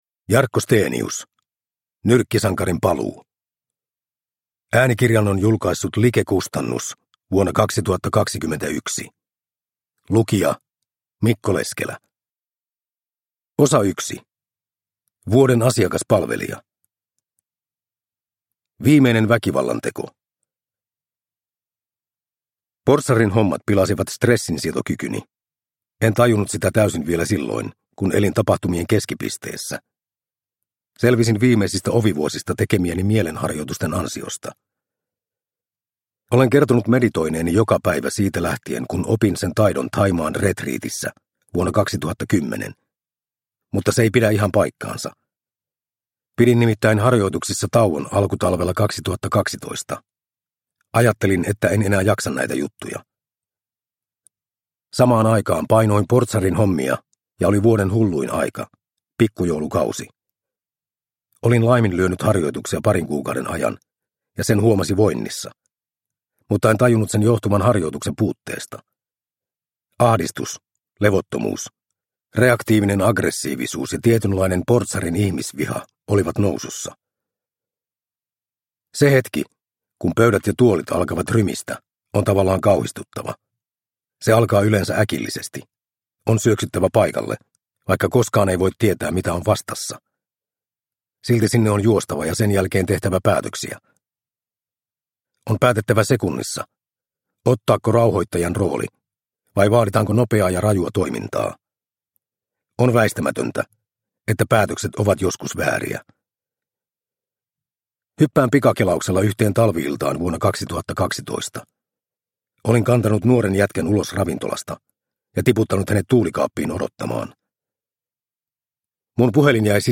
Nyrkkisankarin paluu – Ljudbok – Laddas ner